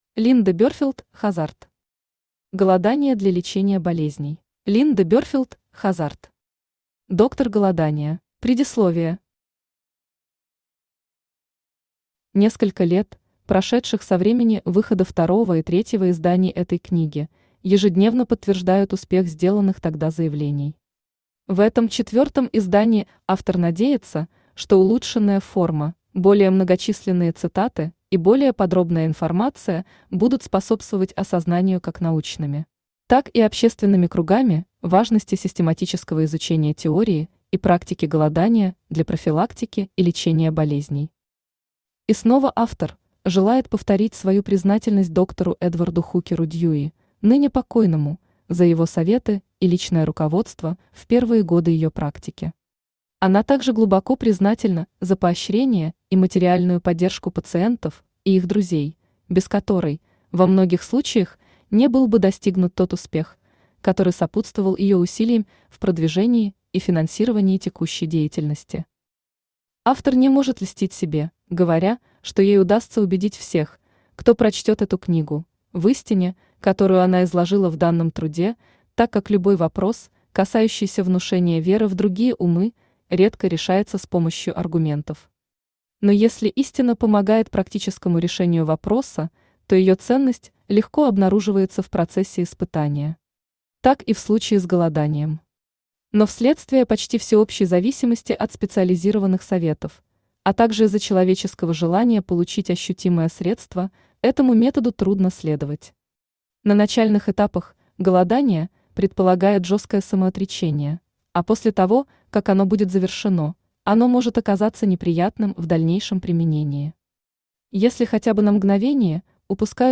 Аудиокнига Голодание для лечения болезней | Библиотека аудиокниг
Aудиокнига Голодание для лечения болезней Автор Линда Берфилд Хаззард Читает аудиокнигу Авточтец ЛитРес.